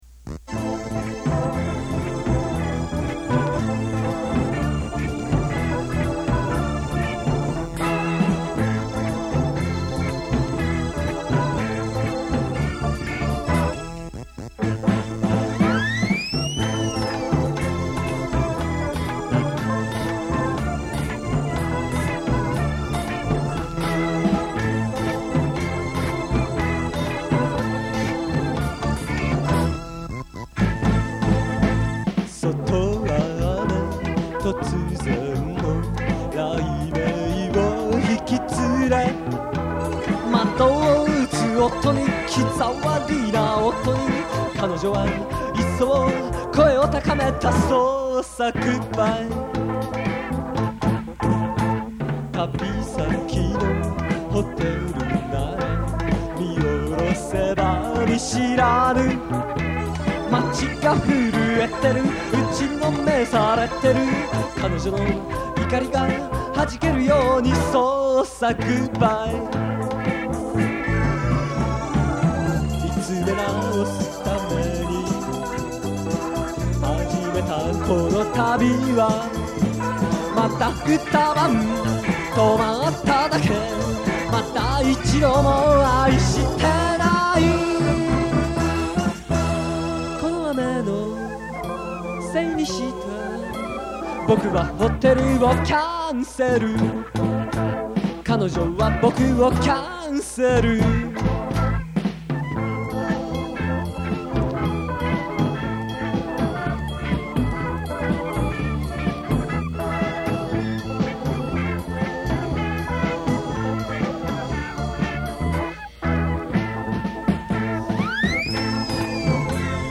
ここで紹介させていただきますのは，もう２０余年前，大学の頃，ひとり軽音楽部の部室で，多重録音で作ったオリジナル曲です。
全部，自分でやってますし，おまけに，おそろしいことに Vocal まで，私自身でやっております（なんせ，男性サイドの歌詞ばかりなもので･･）。